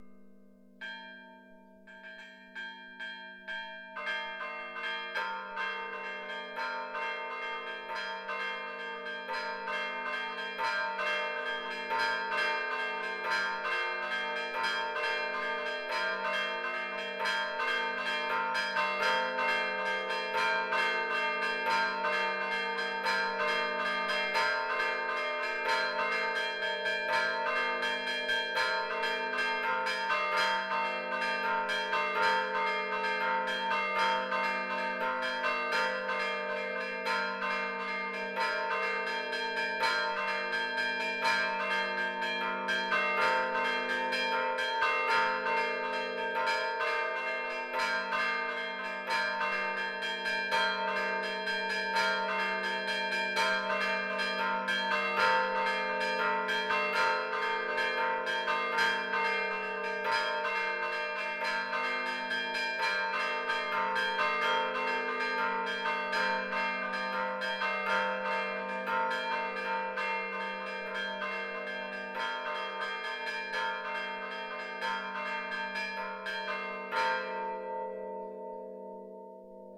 Suonate di campane
dal campanile della Parrocchiale S. Maria del Piano in Verbicaro
suonate rigorosamente a mano, cliccate sui singoli titoli e scaricate il file audio)